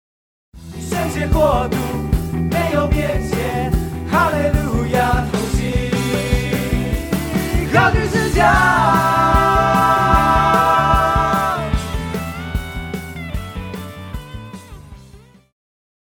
套鼓(架子鼓)
乐团
教会音乐
演奏曲
独奏与伴奏
有主奏
有节拍器